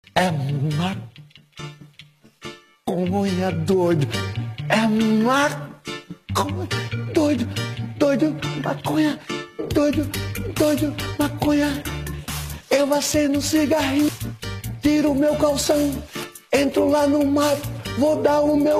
sikeira-jr-canta-reggae-e-maconha-doido-mp3cut.mp3